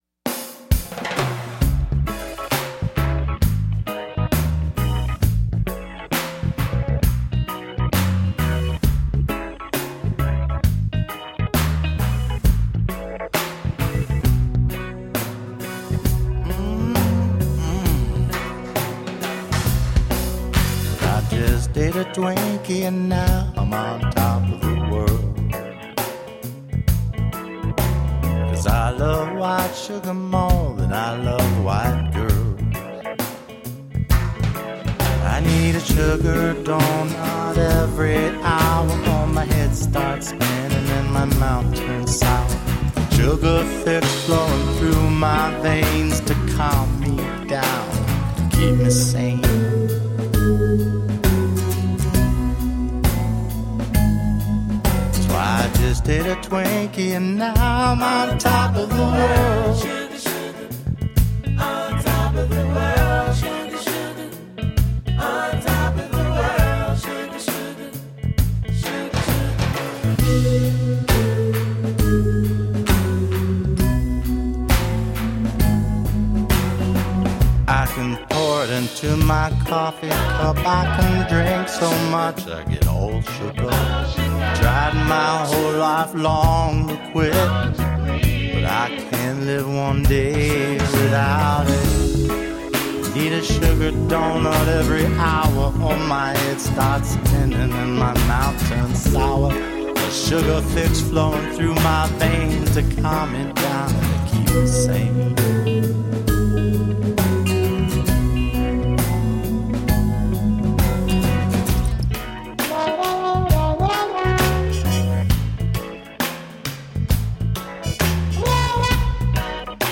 An introspective mix of acoustic guitar based songs.